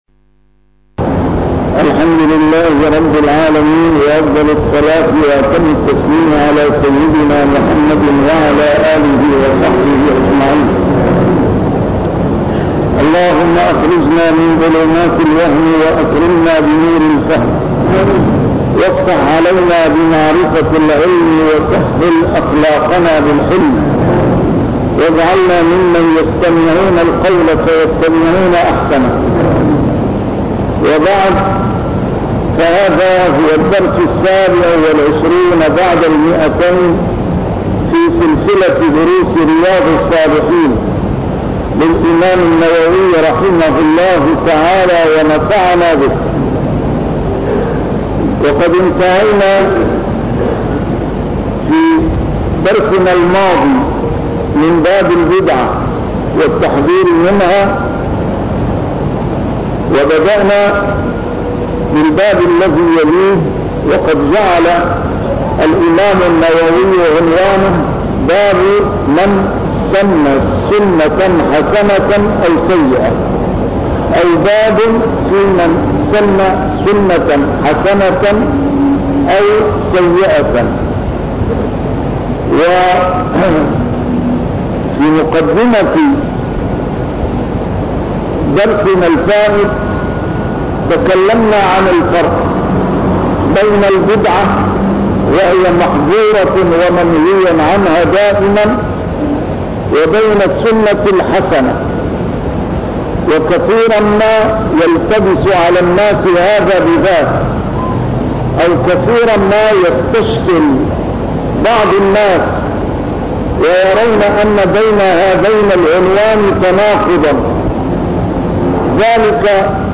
A MARTYR SCHOLAR: IMAM MUHAMMAD SAEED RAMADAN AL-BOUTI - الدروس العلمية - شرح كتاب رياض الصالحين - 227- شرح رياض الصالحين: فيمن سنَّ سنّةً